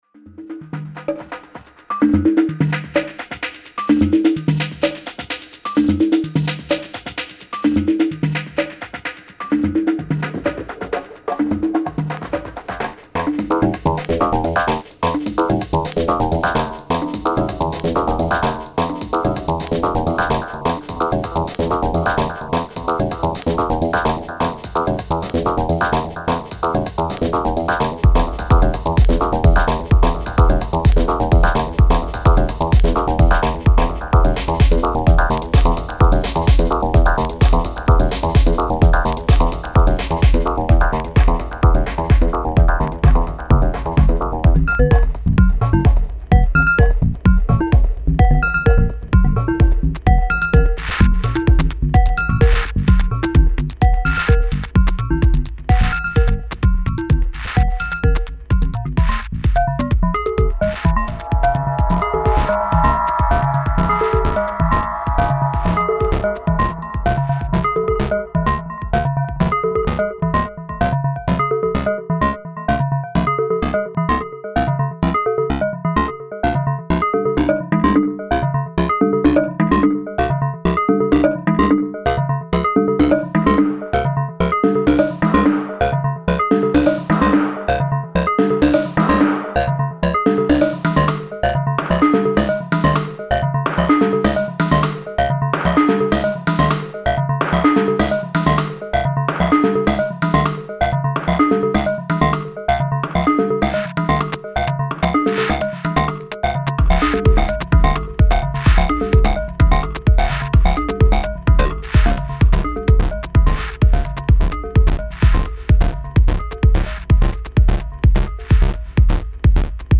Number and duration of shadows created by the player have an impact on loudness, rhythm and sound of 11 different, pre-programmed collection of sounds. This collection of sounds and loops will be arranged by the player who will experience himself as a DJ or a remixer.